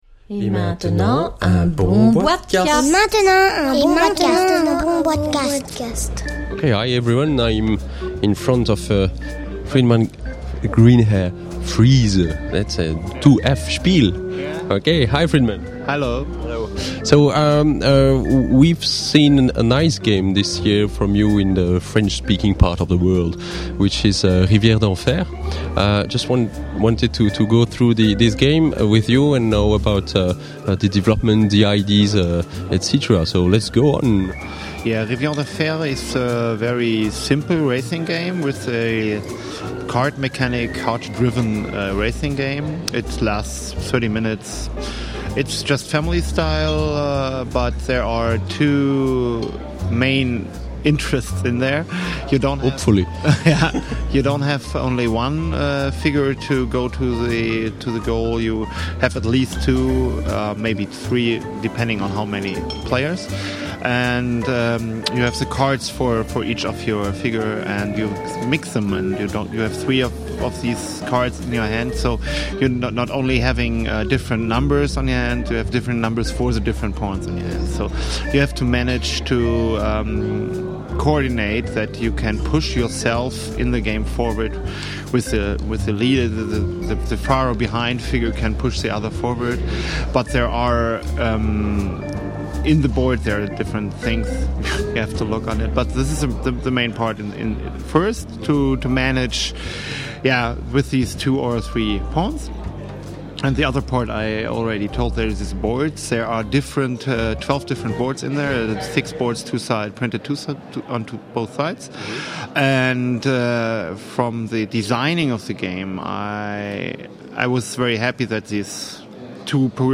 (enregistré lors du Nuremberg SpielenwarenMesse 2009)